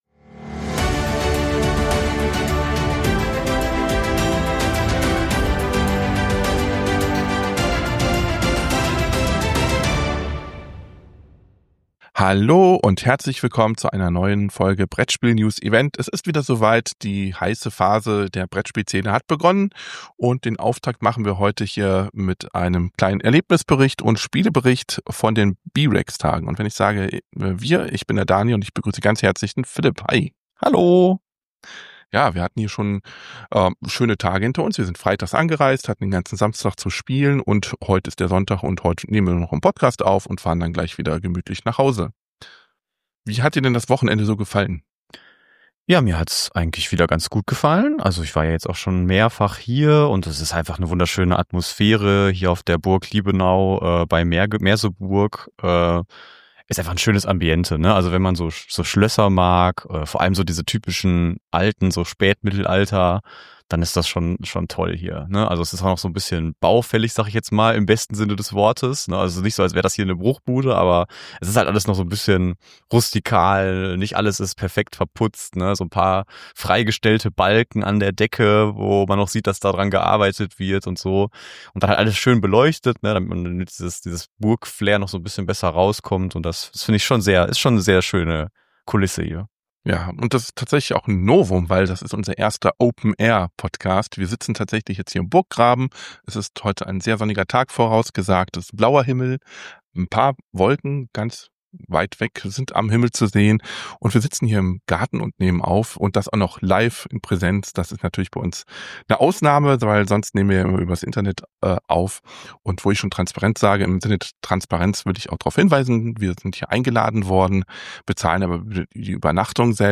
Hier geht es zu unserem Video mit mehr Eindrücken Es ist unser erster Open-Air-Podcast, und wir genießen es, im Garten der Burg zu sitzen, während wir über die Spiele sprechen, die wir getestet haben. Das Wochenende war gefüllt mit aufregenden Spieleerlebnissen, und wir sprechen über die zahlreichen Titel, die wir gespielt haben. Unter anderem startet unser Bericht mit dem Familien-Spiel „Toriki“, das von Lucky Duck Games stammt und im Jahr 2024 veröffentlicht wird.